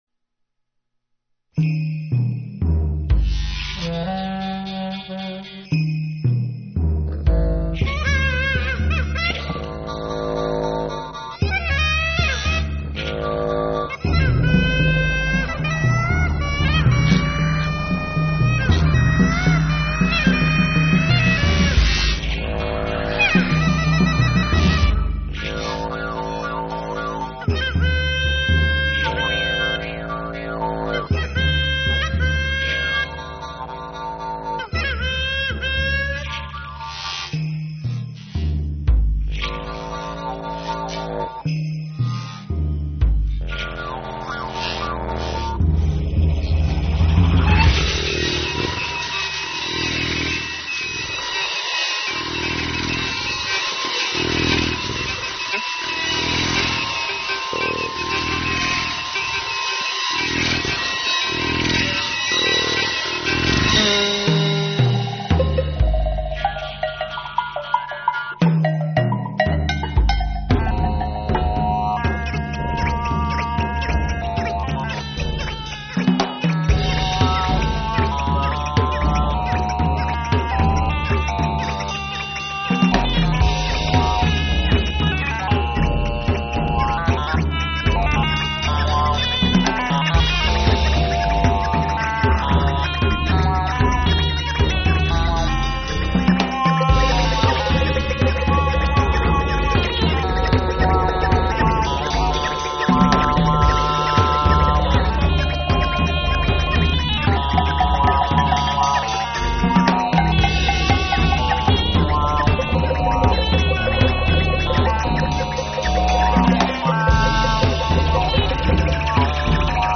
Batterie et percussions